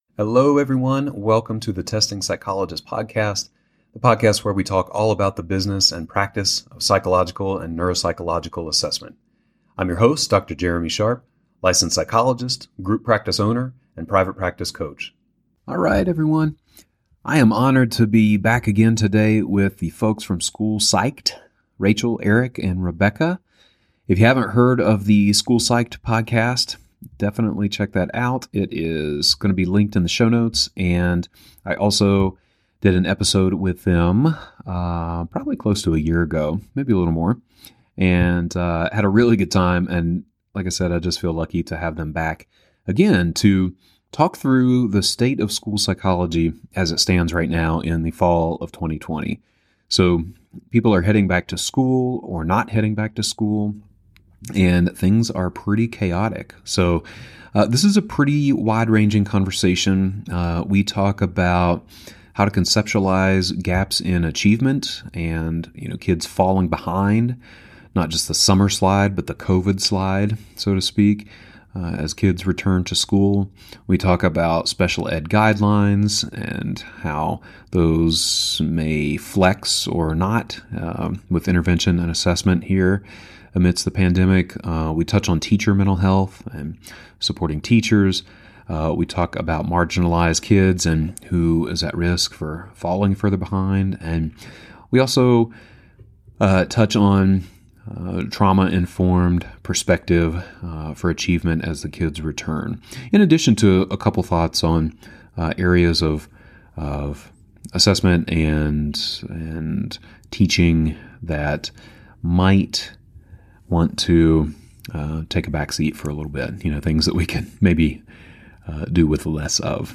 Format: Asynchronous, distance learning. Non-interactive. Recorded audio with transcript.